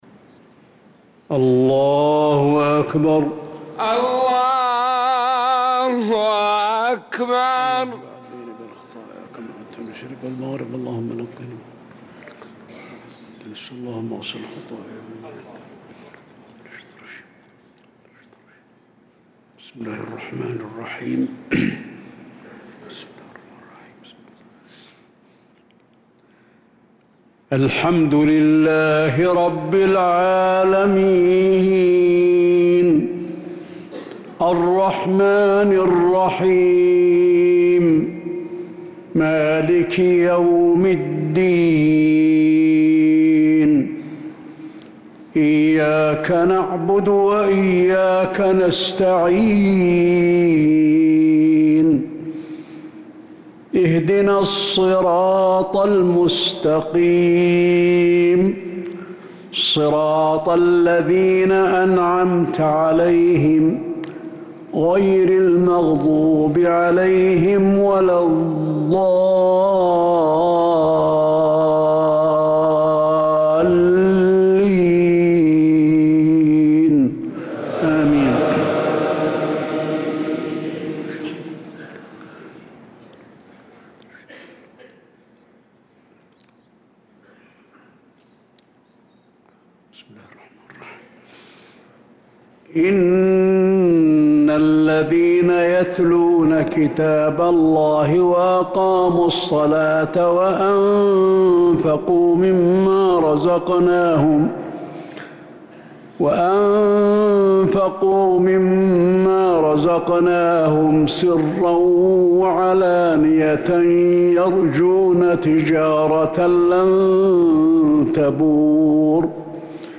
صلاة المغرب للقارئ علي الحذيفي 15 ربيع الآخر 1446 هـ
تِلَاوَات الْحَرَمَيْن .